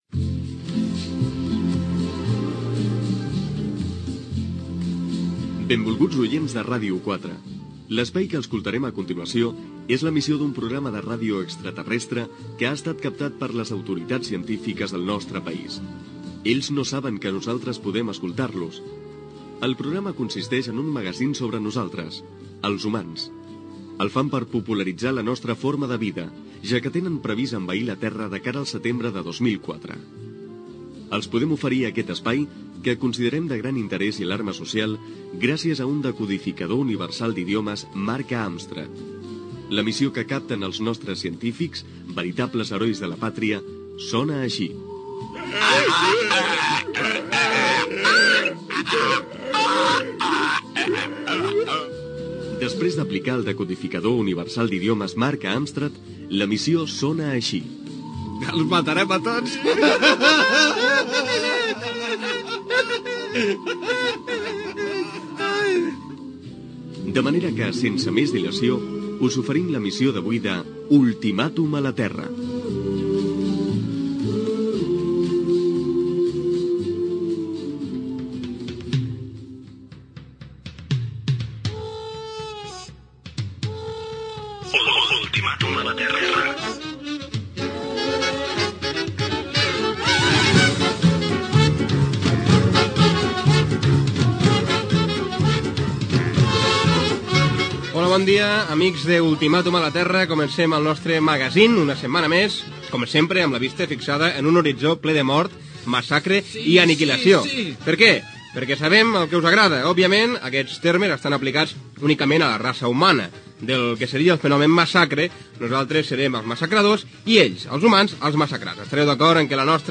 Ficció
FM